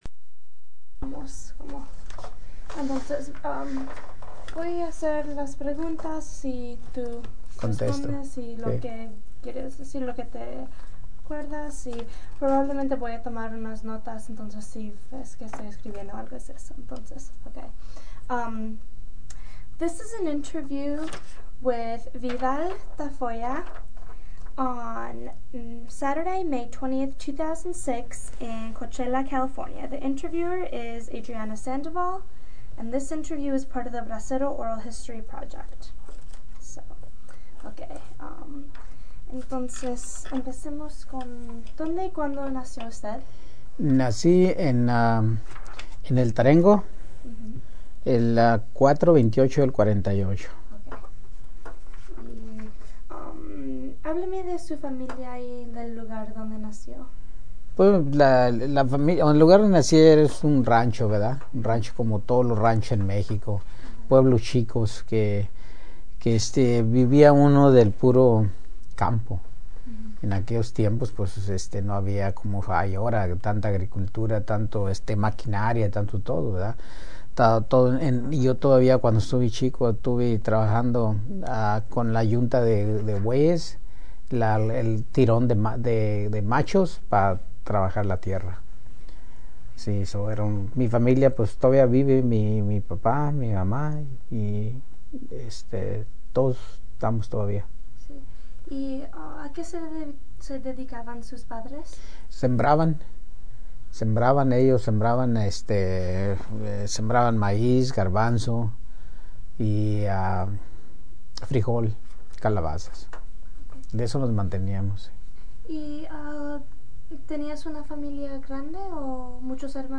Summary of Interview: